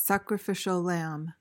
PRONUNCIATION:
(sak-ruh-FISH-uhl lam)